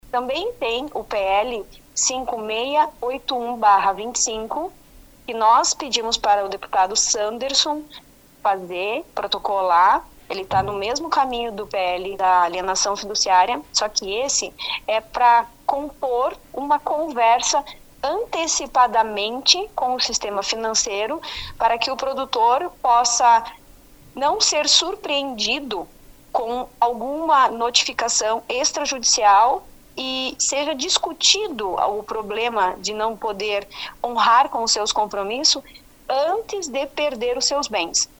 Na mesma entrevista, ontem, as 6 e 30, no programa Progresso Rural da RPI